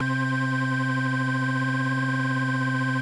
Organ - Rock.wav